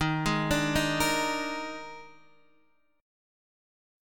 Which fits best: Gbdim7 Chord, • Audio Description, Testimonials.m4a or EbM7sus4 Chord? EbM7sus4 Chord